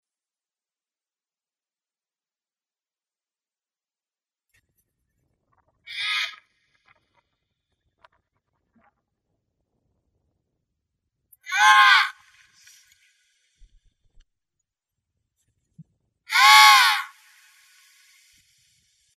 На этой странице вы найдете коллекцию звуков пингвинов: от их забавного «разговора» до шума шагов по льду.
Звук императорского пингвина